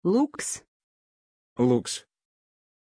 Aussprache von Lux
pronunciation-lux-ru.mp3